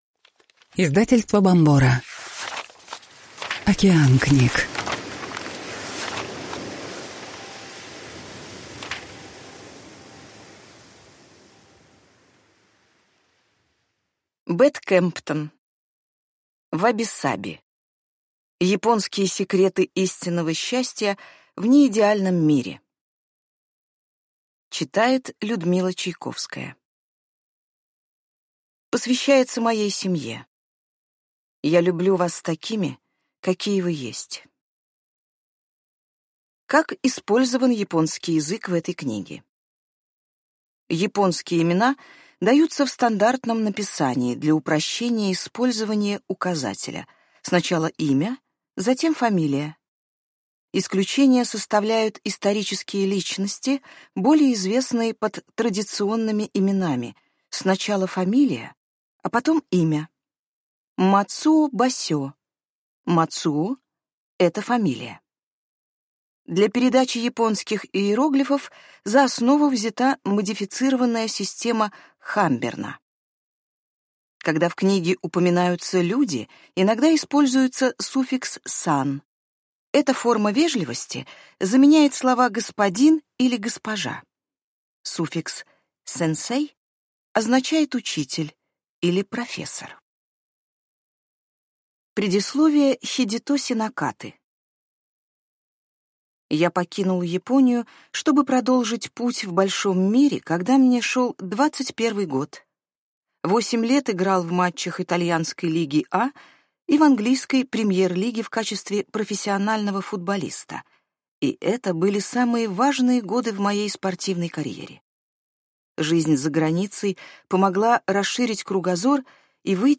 Аудиокнига Wabi Sabi. Японские секреты истинного счастья в неидеальном мире | Библиотека аудиокниг